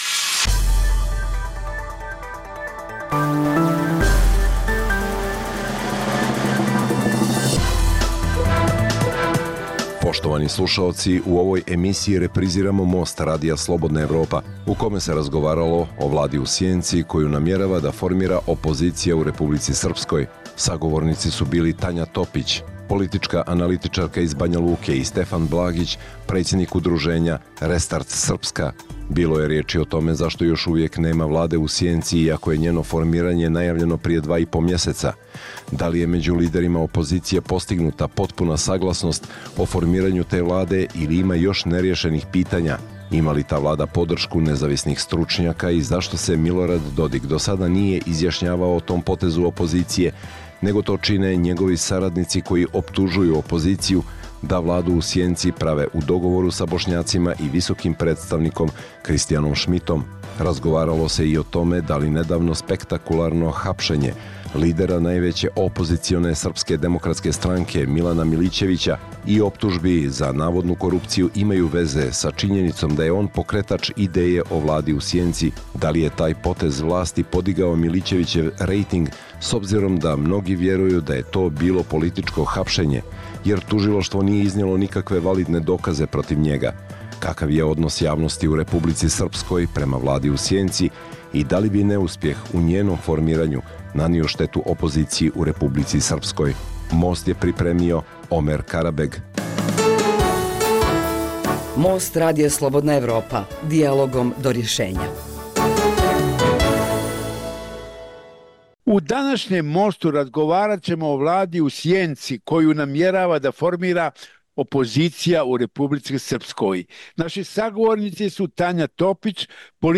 Dijaloška emisija o politici, ekonomiji i kulturi koju uređuje i vodi